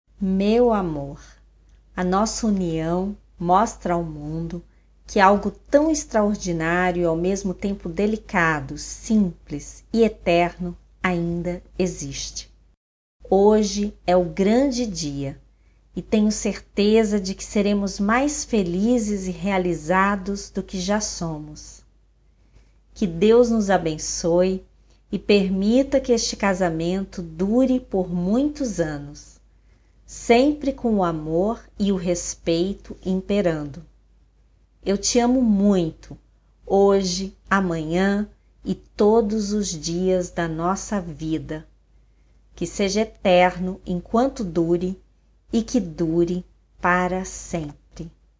Co-Rednerin in der Sprache Portugiesisch